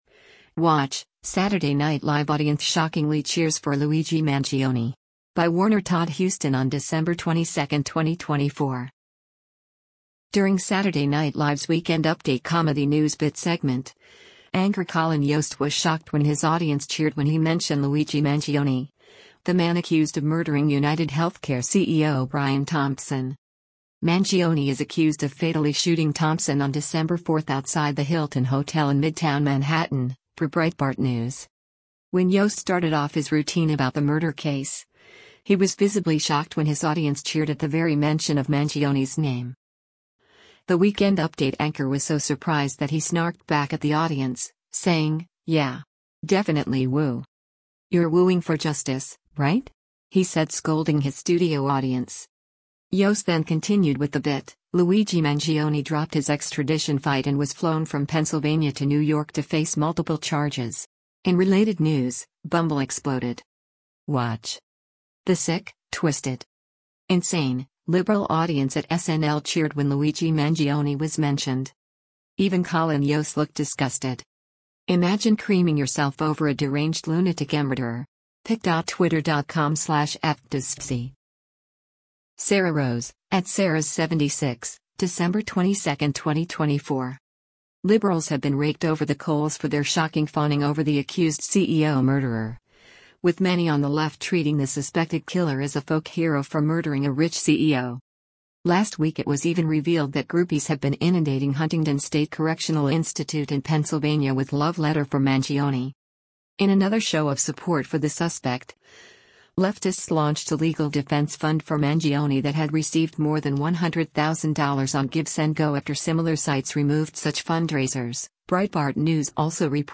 During Saturday Night Live’s “Weekend Update” comedy news bit segment, anchor Colin Jost was shocked when his audience cheered when he mention Luigi Mangione, the man accused of murdering UnitedHealthcare CEO Brian Thompson.
When Jost started off his routine about the murder case, he was visibly shocked when his audience cheered at the very mention of Mangione’s name.
“You’re wooing for justice, right?” he said scolding his studio audience.